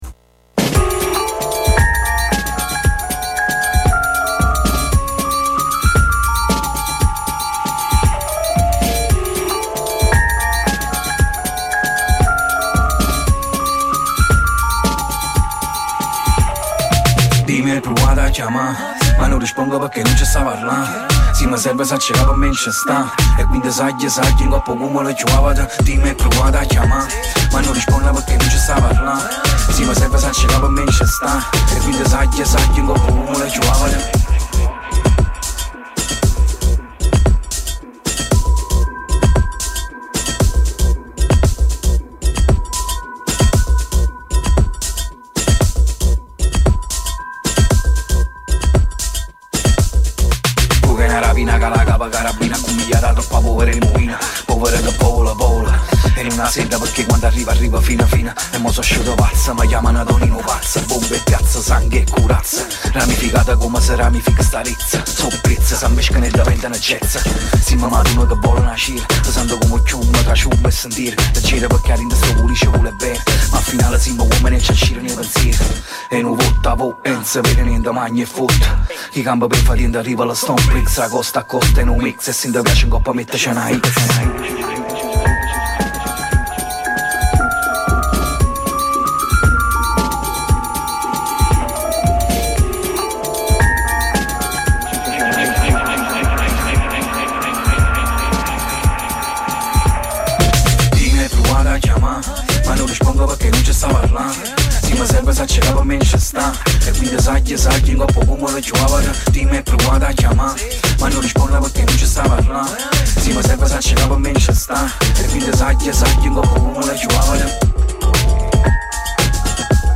Chiacchiere, attitudine sana e viaggi musicali fra vari stili e generi musicali